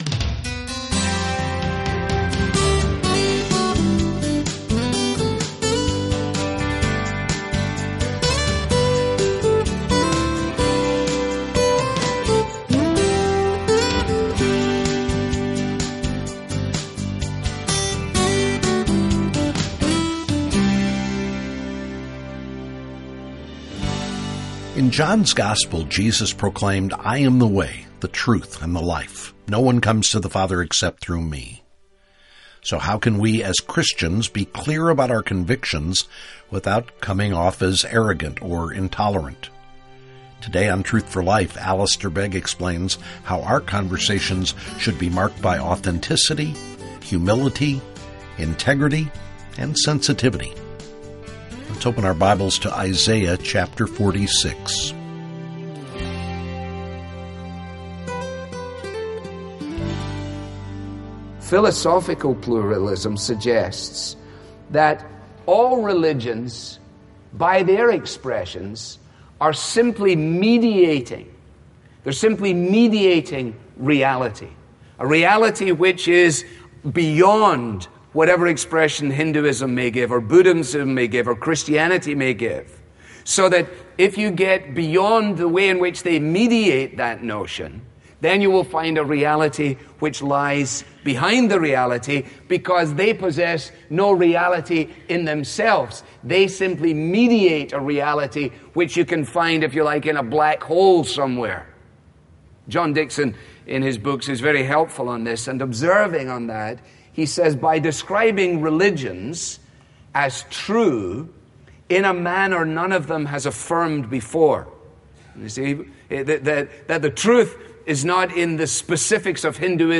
• This program is part of a special sermon ‘There Is No Other Stream — Part Two’ • Learn more about our current resource, request your copy with a donation of any amount. Helpful Resources - Learn about God's salvation plan - Read our most recent articles - Subscribe to our daily devotional Follow Us YouTube | Instagram | Facebook | Twitter This listener-funded program features the clear, relevant Bible teaching of Alistair Begg.